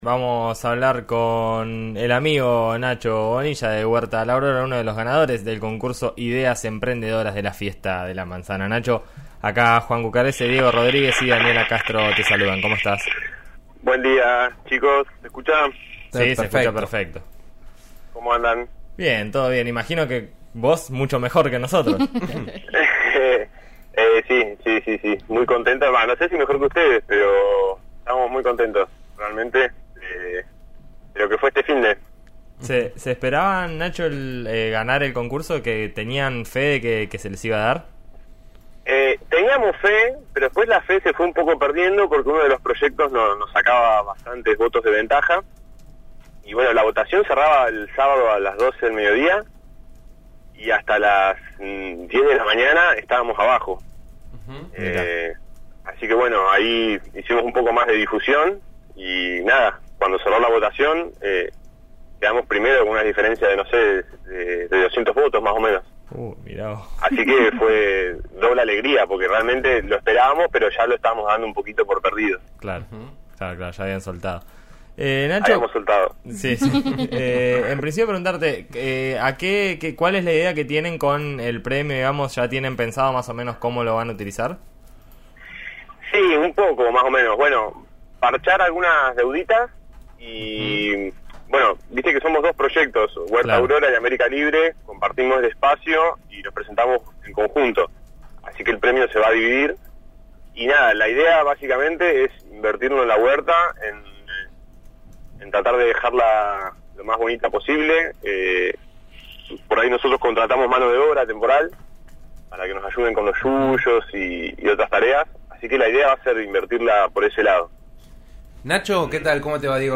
Ideas Emprendedoras: una charla con Huerta Aurora, vanguardia agroecológica